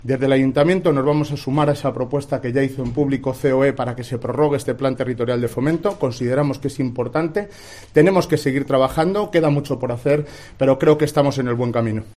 AUDIO / Alcalde - prorroga